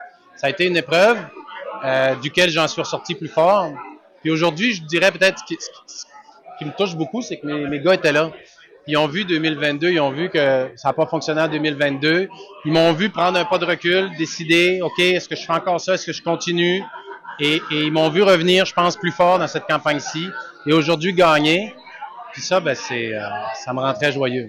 Alexis Deschênes, lors de son discours, le soir des élections.